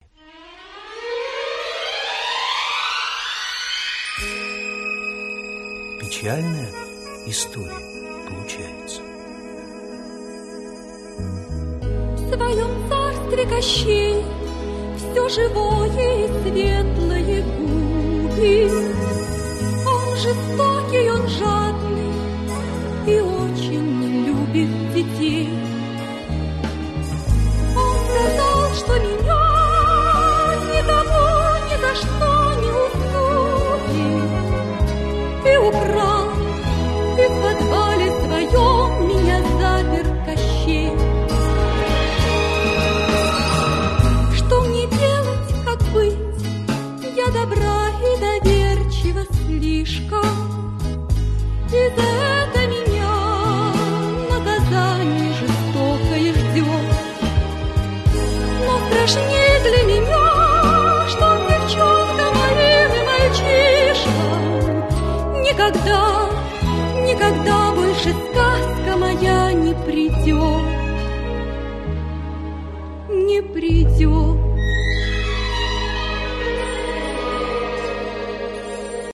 полна сожаления и печали